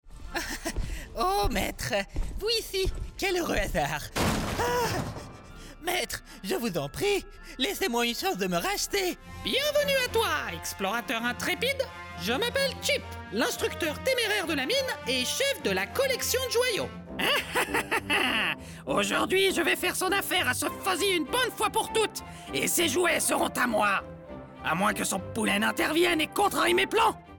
中低音域の声で、暖かさと親しみやすさを兼ね備えています。
コミカルな(フランス語)